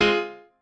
piano8_18.ogg